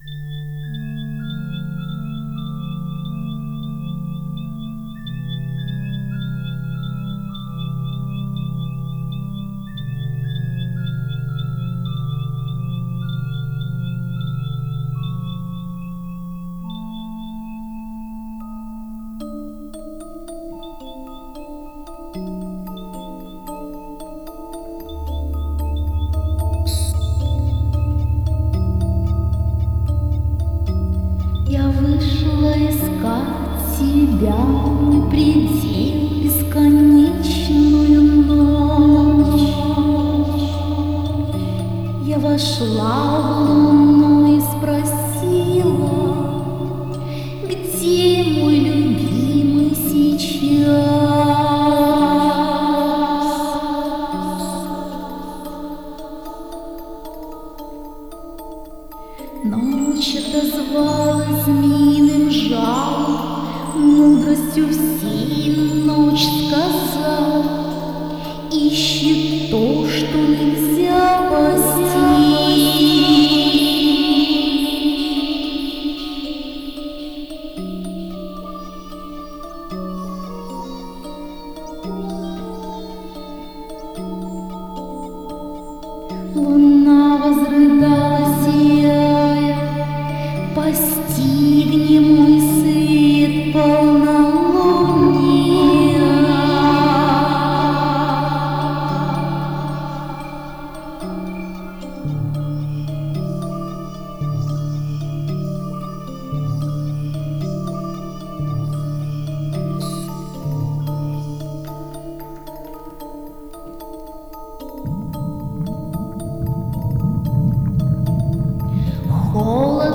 Духовная музыка Медитативная музыка Мистическая музыка